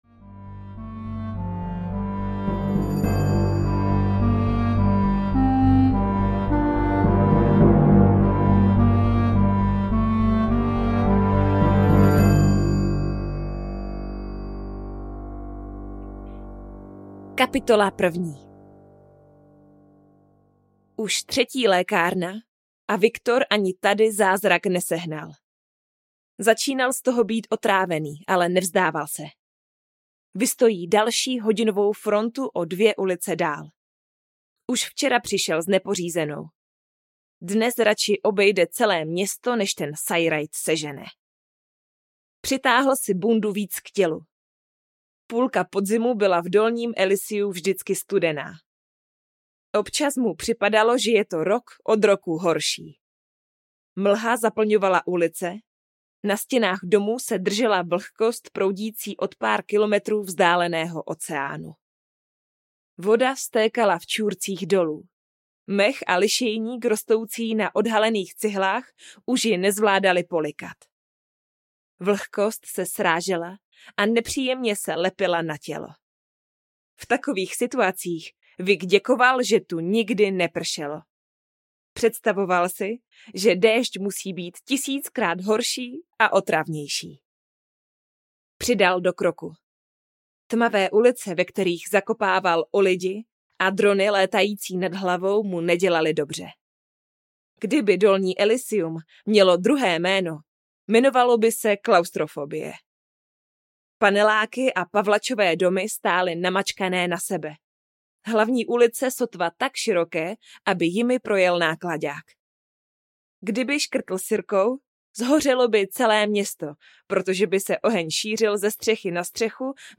O dracích a sedmi audiokniha
Ukázka z knihy